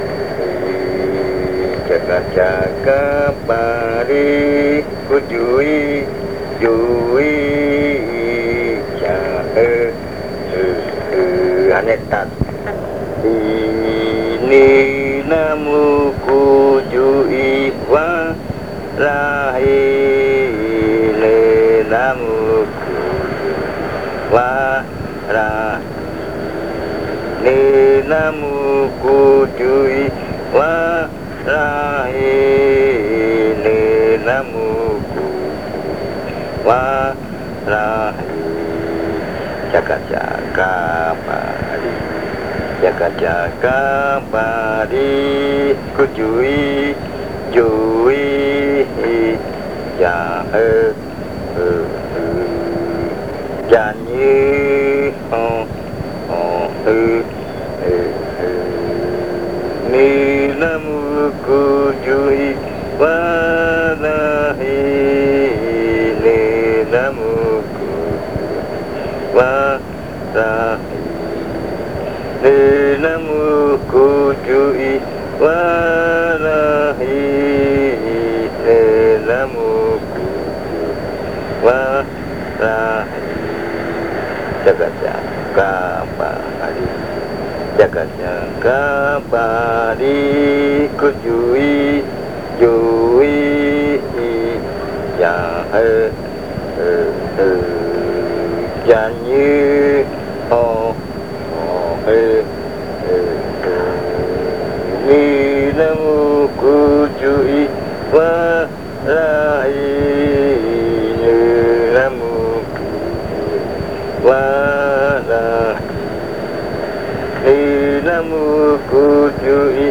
Leticia, Amazonas
Canto de baile frutas en lengua ocaina
Fruit dance chant in Ocaina language